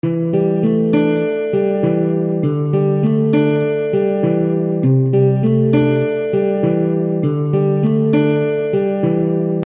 醇厚的原声旋律
Tag: 100 bpm Acoustic Loops Guitar Electric Loops 1.62 MB wav Key : Unknown